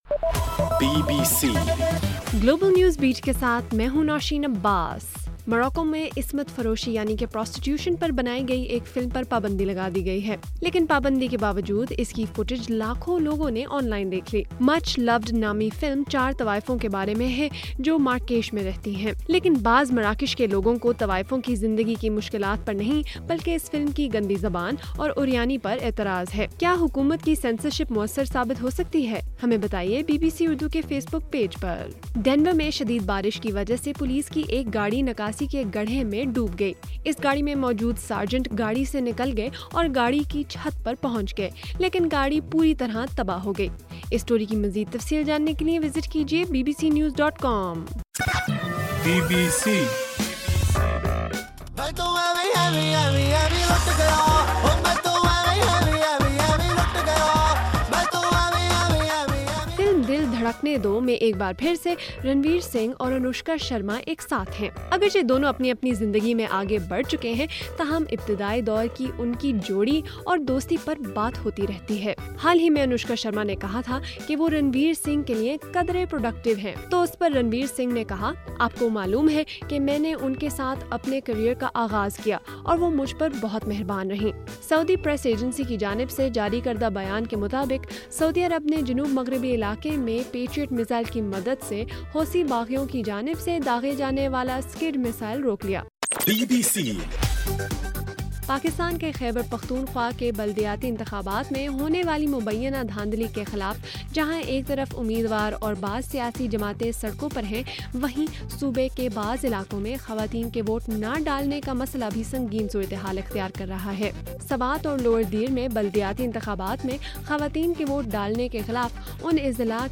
جون 6: رات 8 بجے کا گلوبل نیوز بیٹ بُلیٹن